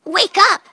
synthetic-wakewords
ovos-tts-plugin-deepponies_Scootaloo_en.wav